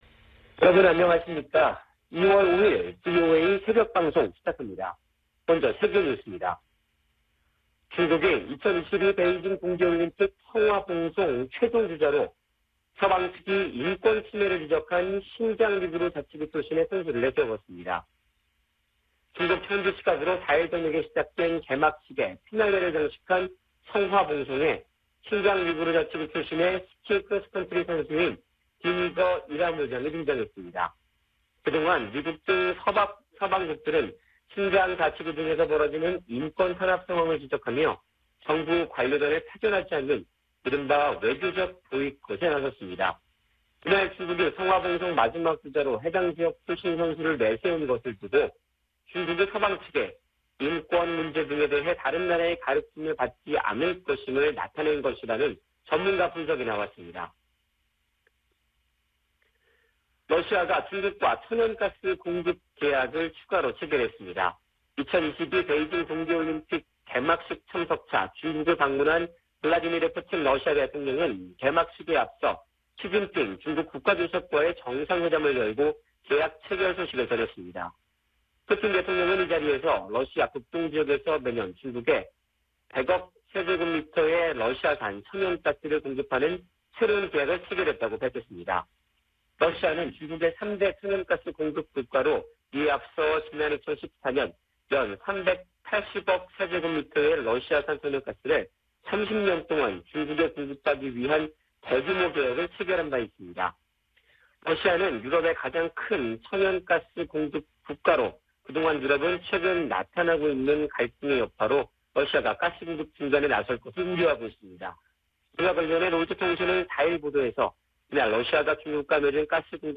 생방송 여기는 워싱턴입니다 2022/2/5 아침